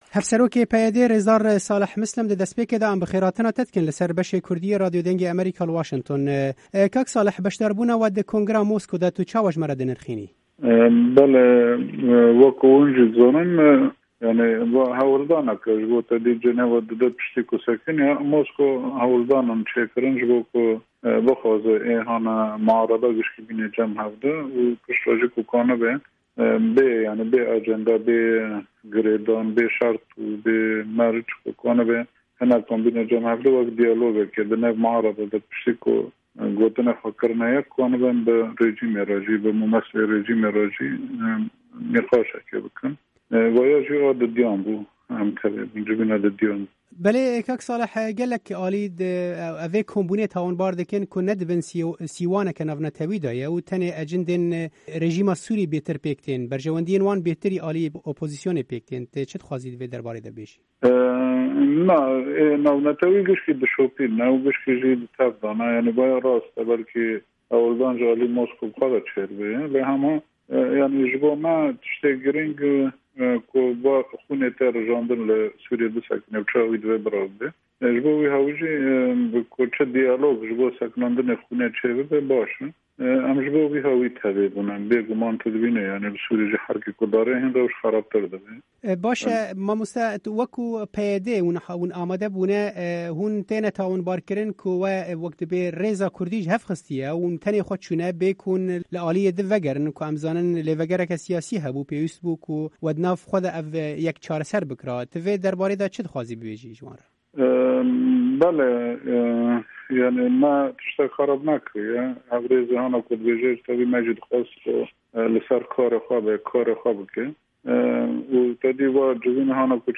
Hevpeyvîn bi Salih Mislim re